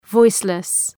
Προφορά
{‘vɔıslıs}